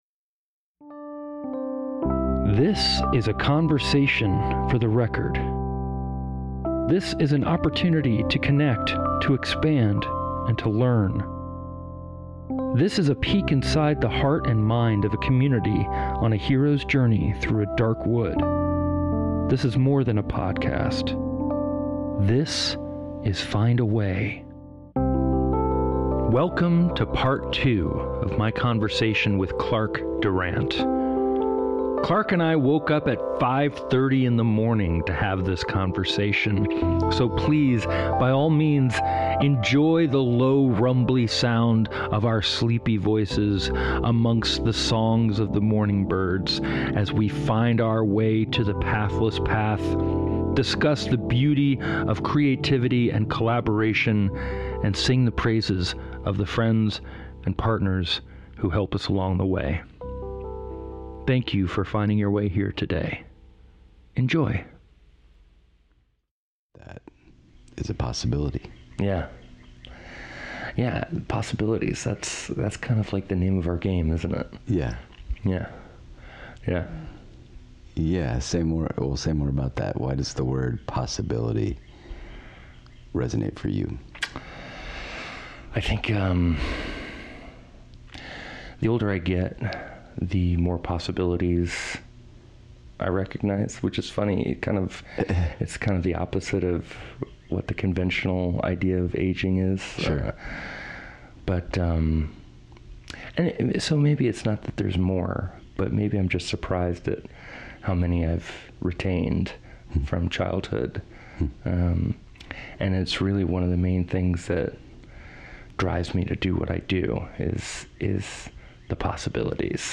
Welcome to the second part of my conversation with musician